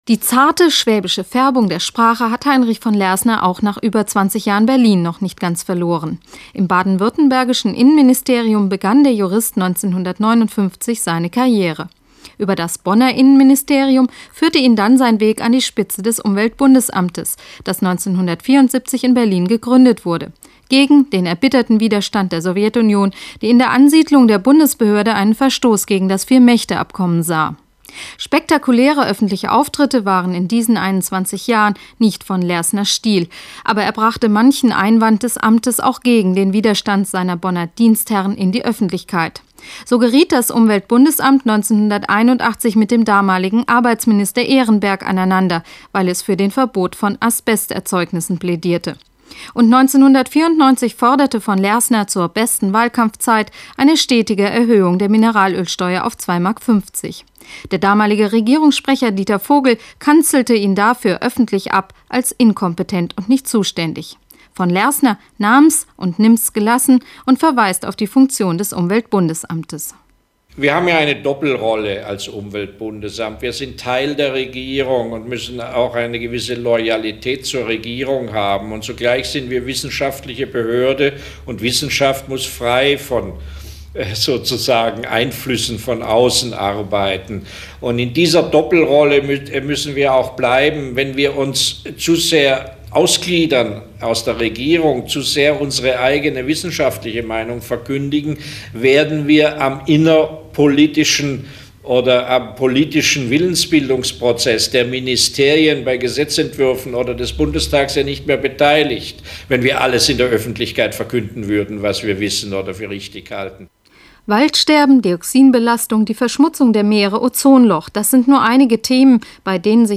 Radiobeitrag zum Wechsel an der Spitz des Umweltbundesamtes 1995: Von Lersner geht – Troge kommt (Deutschlandfunk 1995, gekürzt)